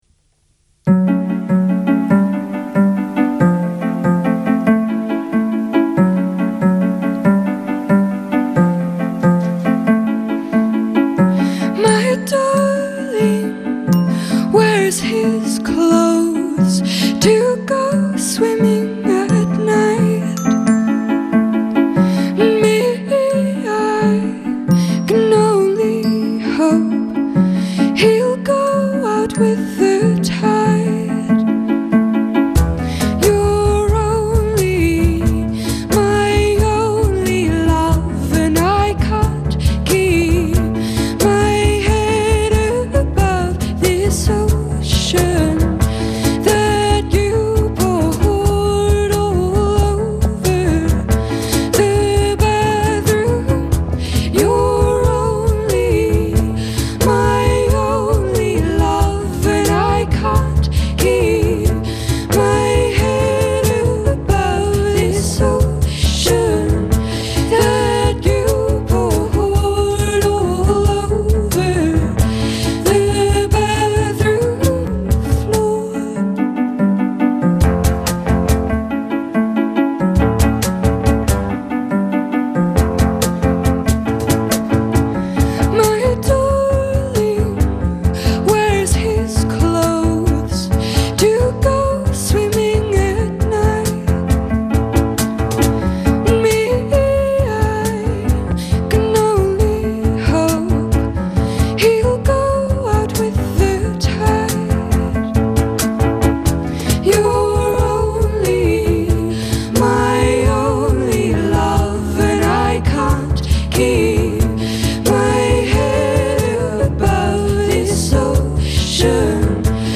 live on bbc 6 music